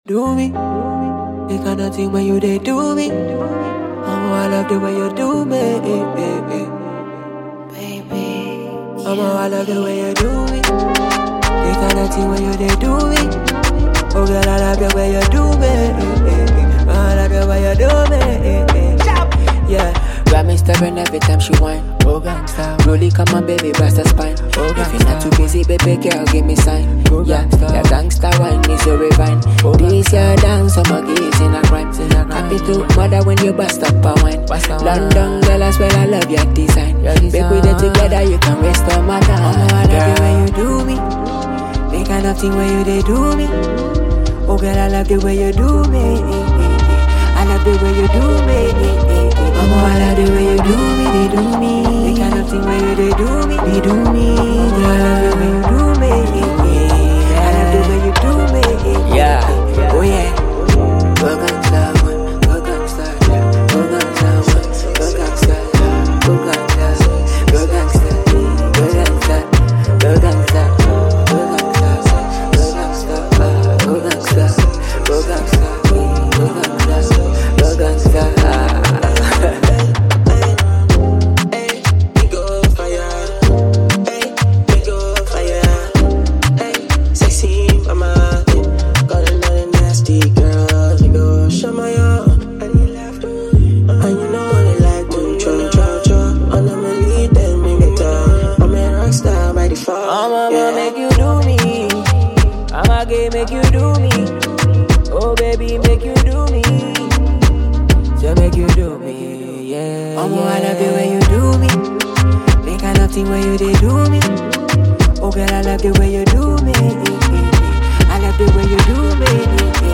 for this appetizing and melodious record.